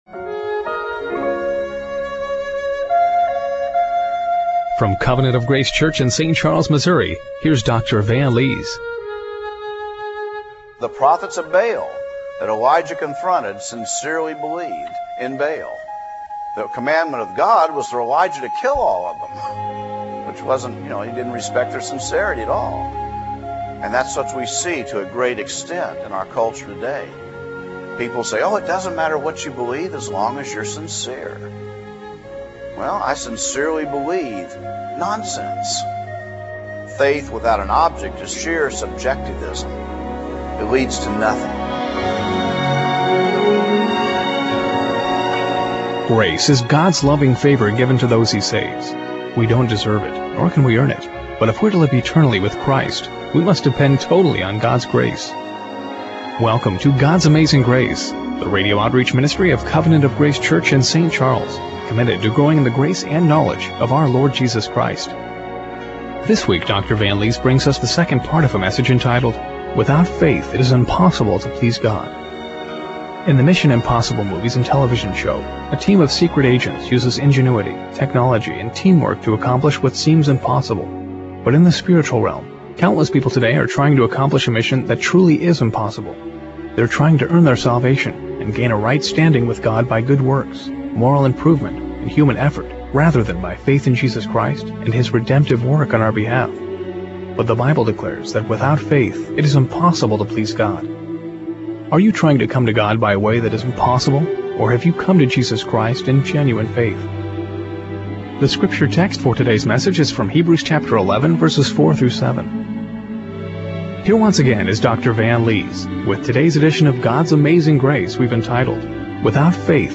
Hebrews 11:4-7 Service Type: Radio Broadcast Are you trying to come to God by a way that is impossible -- or have you come to Jesus in genuine faith?